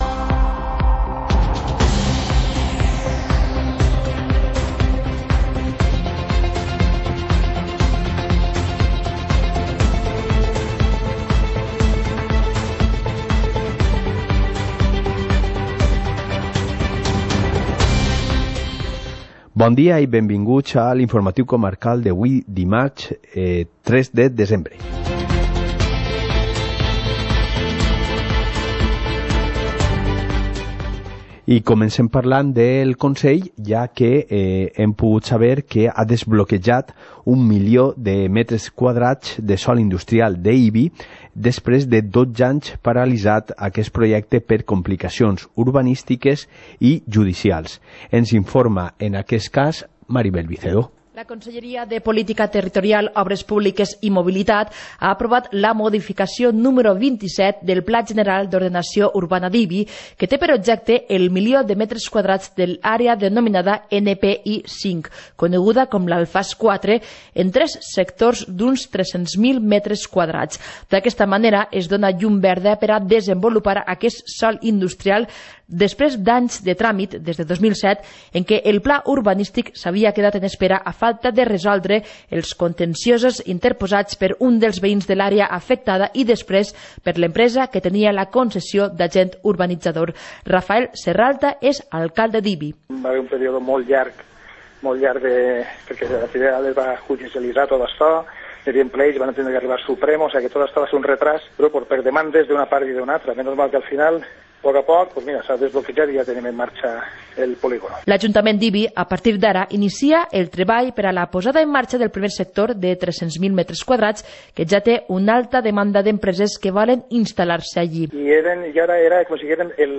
Informativo comarcal - martes, 03 de diciembre de 2019